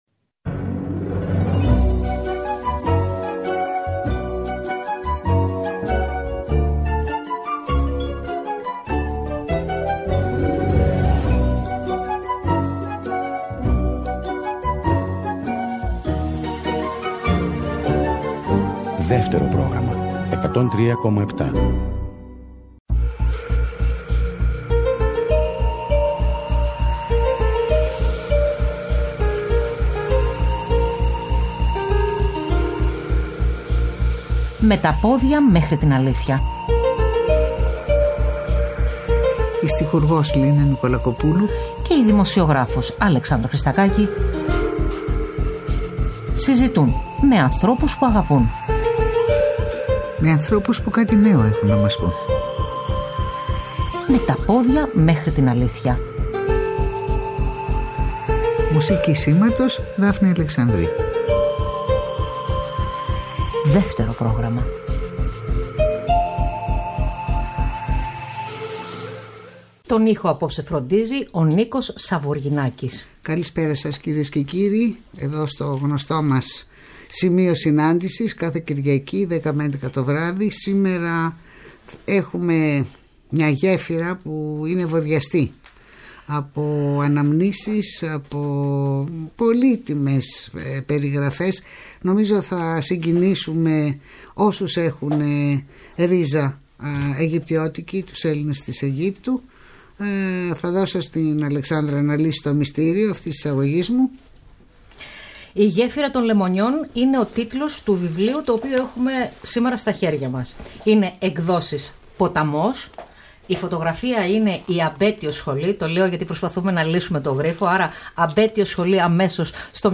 Ο Κώστας Φέρρης σκηνοθέτης, θεατρικός συγγραφέας, σεναριογράφος, μουσικός παραγωγός, θεωρητικός του κινηματογράφου, δημοσιογράφος, τηλεοπτικός παρουσιαστής, και ενίοτε… ηθοποιός και τραγουδιστής καλεσμένος στις 23 Απριλίου 2023 στην εκπομπή ” Με τα πόδια μέχρι την αλήθεια“.